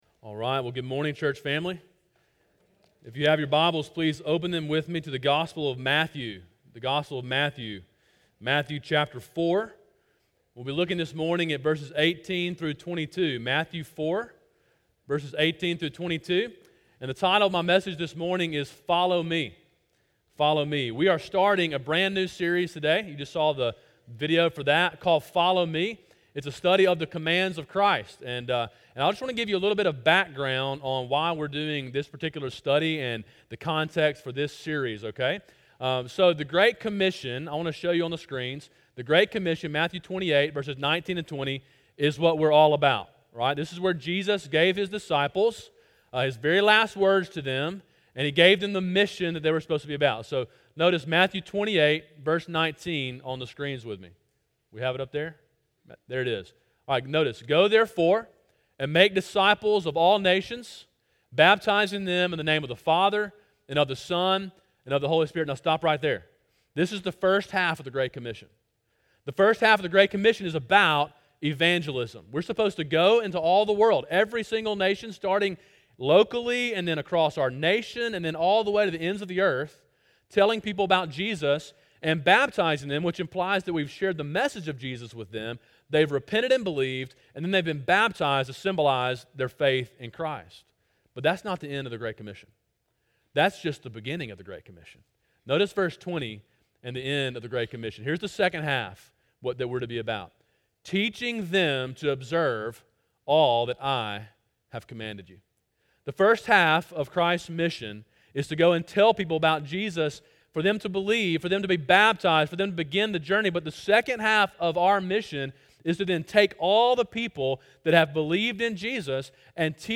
Sermon: “Follow Me” (Matthew 4:18-22) – Calvary Baptist Church